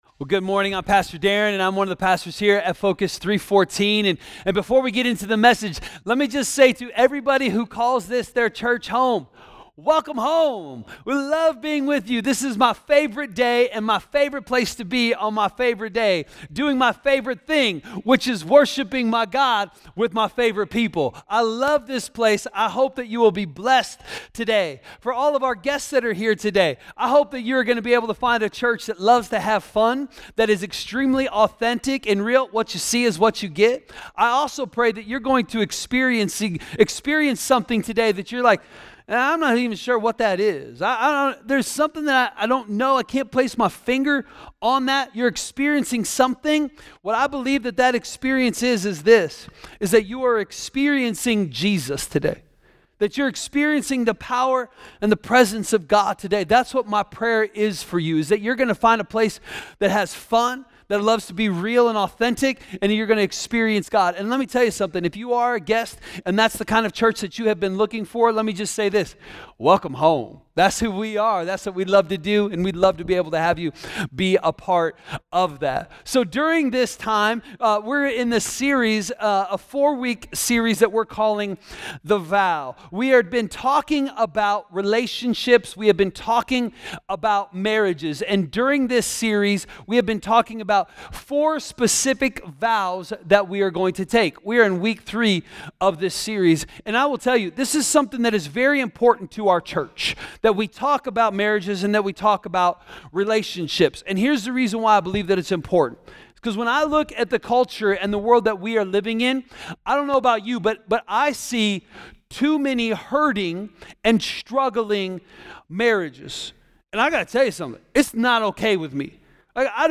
A message from the series "The Vow." Wedding vows are more than a declaration of love—they hold the keys to a strong, lasting marriage.